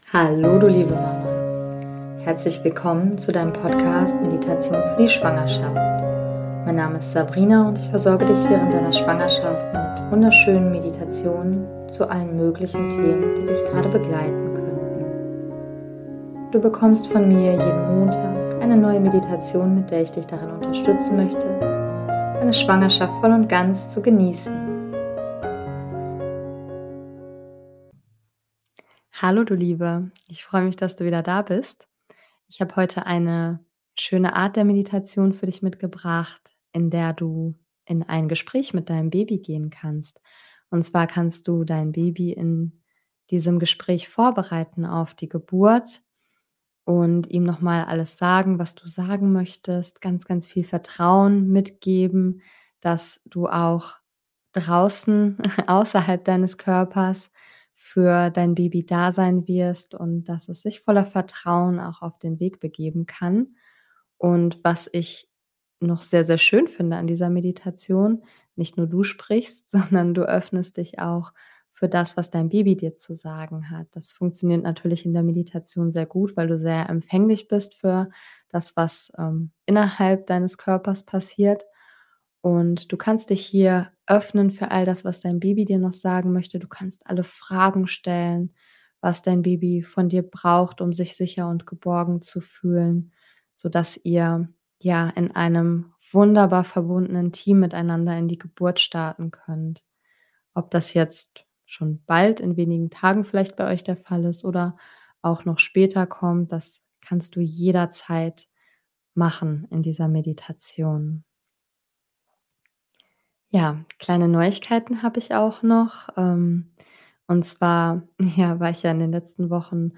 In dieser Schwangerschaftsmeditation richtest du dich voll und ganz auf dein Baby aus. Du gibst dir die Möglichkeit, deinem Baby zuzuhören und es nach seinen Wünschen und Bedürfnissen zu Fragen.